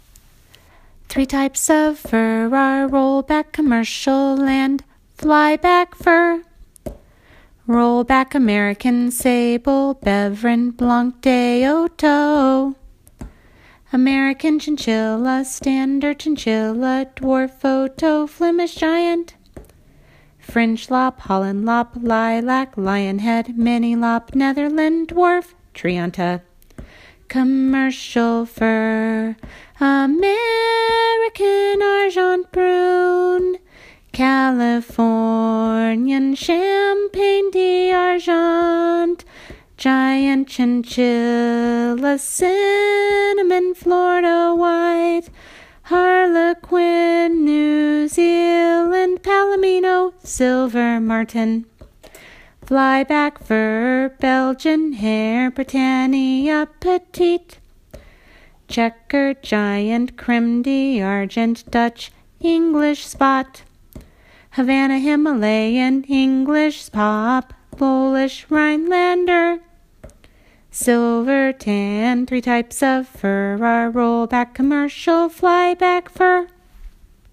Then, I took yet another step down the rabbit hole of super-kooky and… created songs and chants.